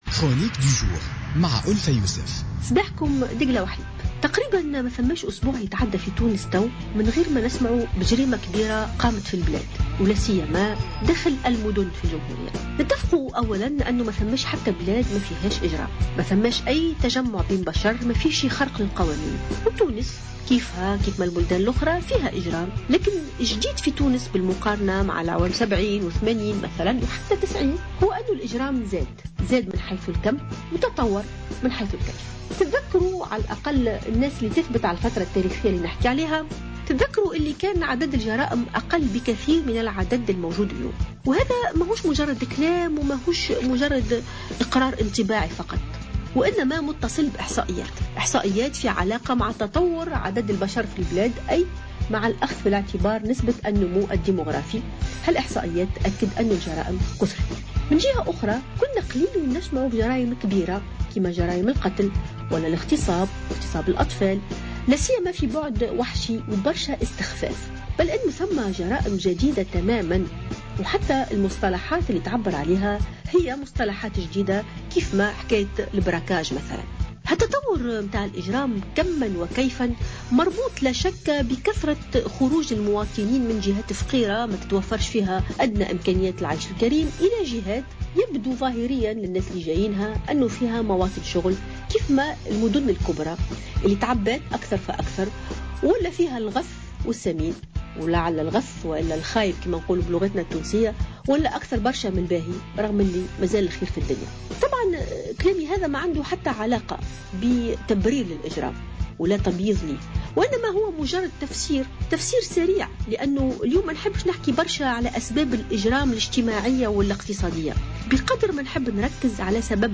تحدثت المفكرة والجامعية ألفة يوسف في افتتاحية اليوم الأربعاء عن ارتفاع نسبة الجريمة وأسبابها.